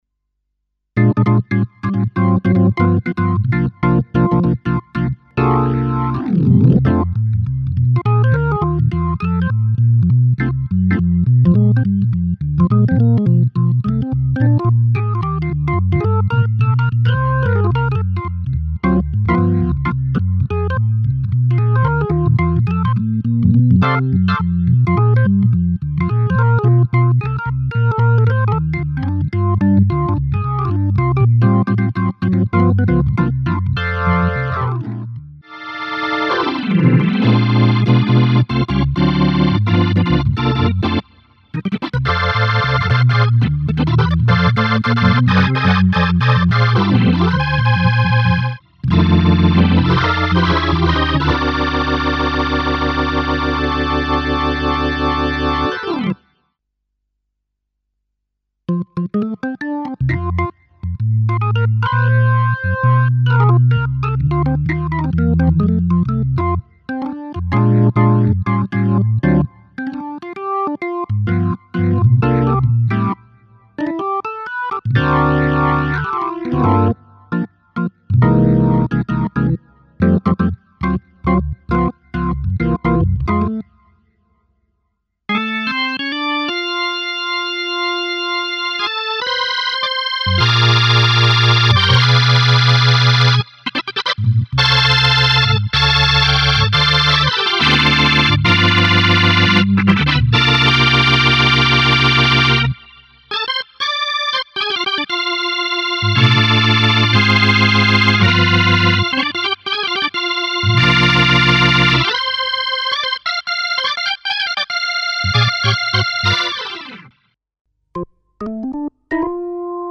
Il primo clip è il CX3 di Kronos che suona come vorrei che suonasse, ascoltate il balance fra 888 e full; il secondo clip è il CX3 così come suona, notate che anche l'888 è molto più aspro e il full proprio non si può sentire; il terzo clip invece il Mojo, che confronto al CX3, come notate, suona molto più pieno, morbido e bilanciato, in altre parole è più leggero all'ascolto e sicuramente molto più veritiero.
Boh, in effetti è un po'aspro il Full del CX3, però a me non dispiace, dovessi scegliere, mi piace più degli altri, che nella mia testa non identifico affatto come Full.